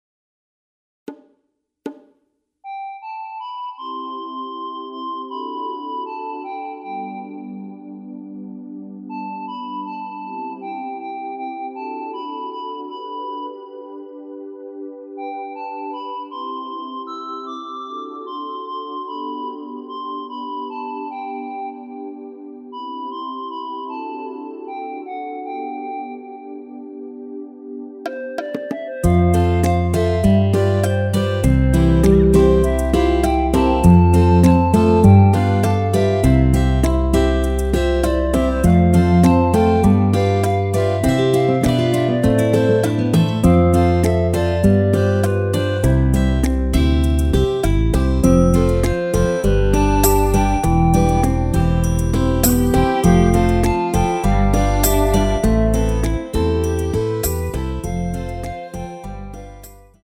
시작부분이 반주가 없이 진행 되는곡이라 스트링으로 편곡 하여놓았습니다.(미리듣기 참조)
원키에서(+6)올린 멜로디 포함된 MR입니다.
노래방에서 노래를 부르실때 노래 부분에 가이드 멜로디가 따라 나와서
앞부분30초, 뒷부분30초씩 편집해서 올려 드리고 있습니다.